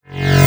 VEC3 Reverse FX
VEC3 FX Reverse 19.wav